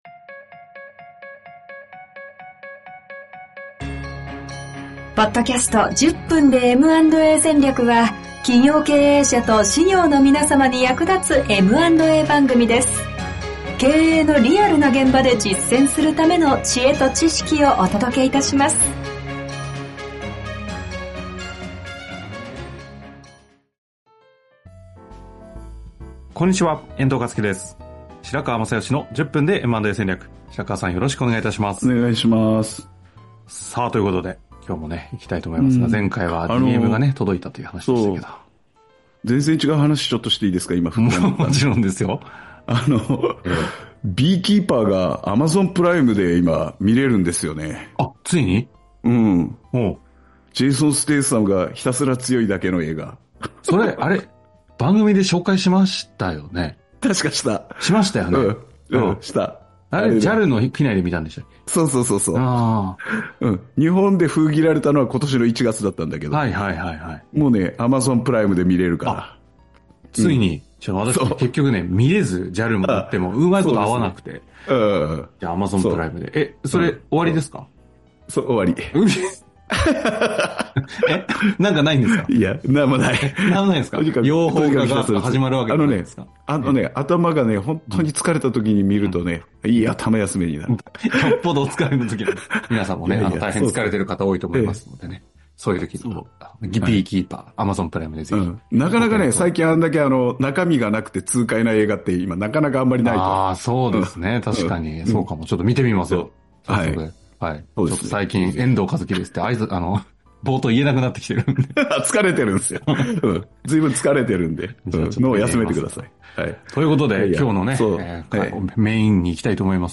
▼2025年1月13日に開催された公開収録の内容をお届けします。